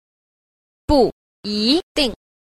3. 不一定 – bù yīdìng – không nhất định